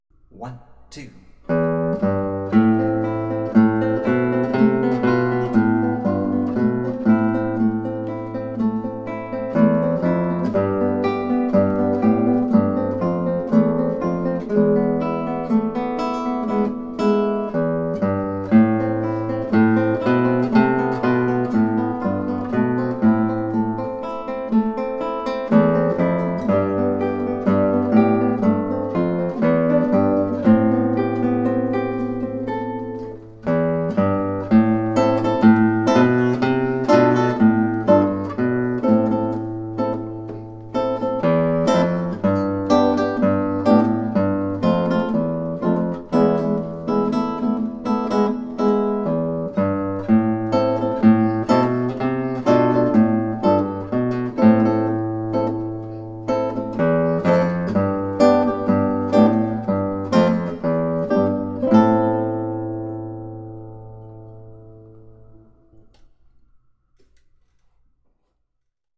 Melody and chords together